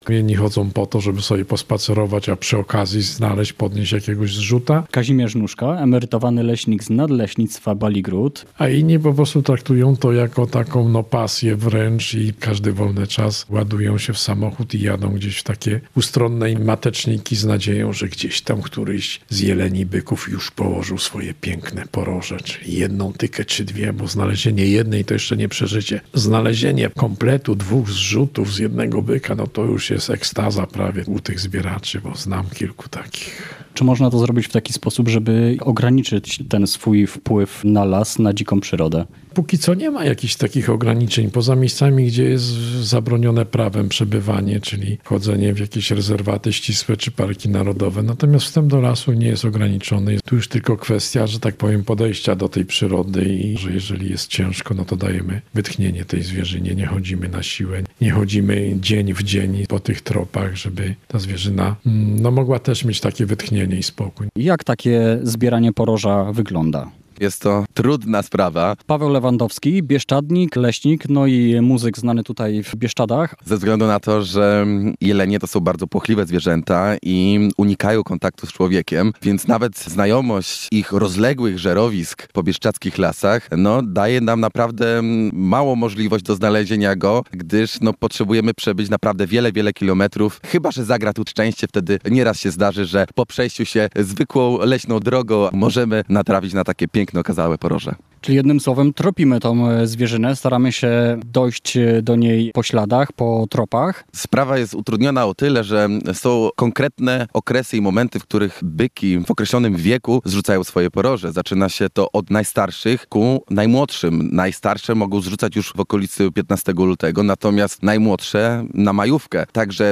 Straż Leśna rusza przeciw nieetycznym zbieraczom • Relacje reporterskie • Polskie Radio Rzeszów